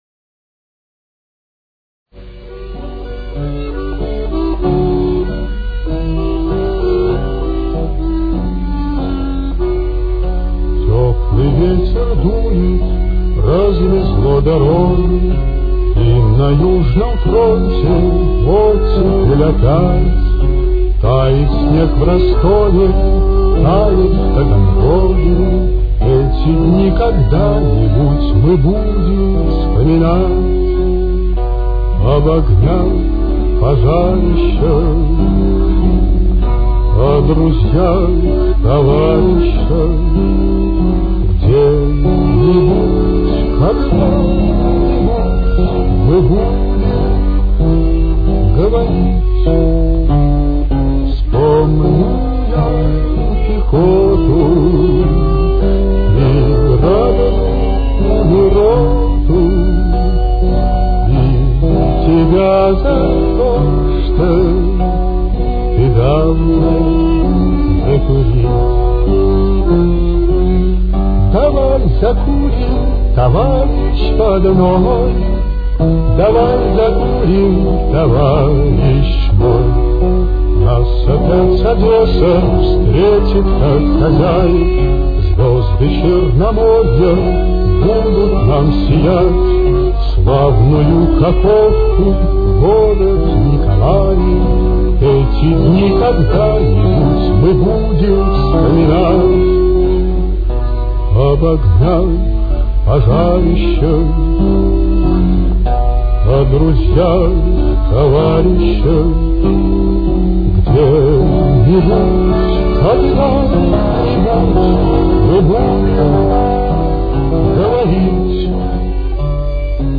Темп: 100.